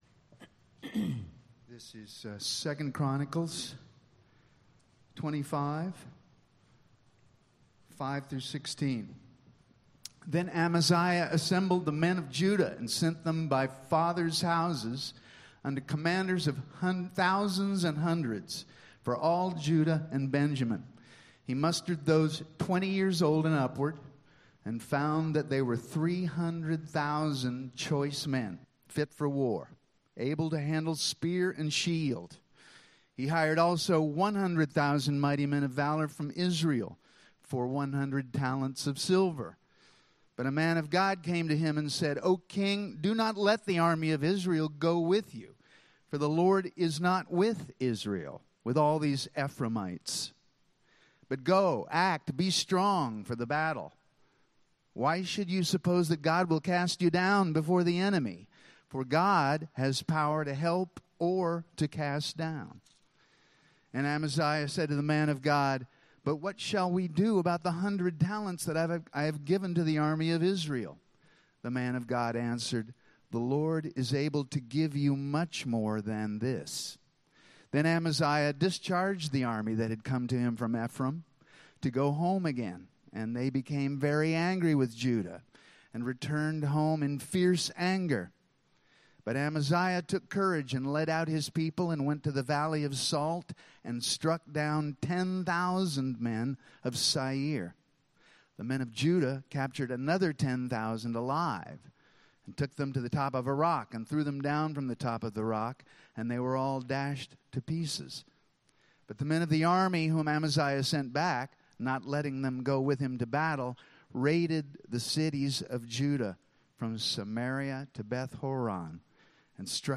Content from Metro Calvary Sermons